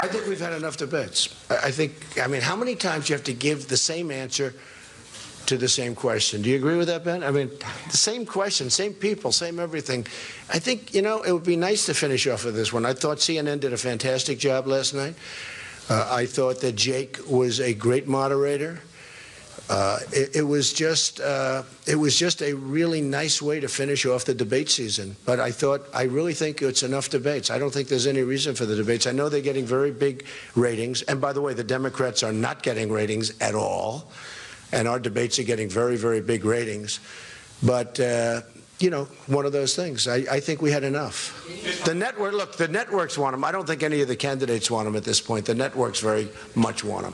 Here is a longer version of Donald Trump's remarks in Florida this morning.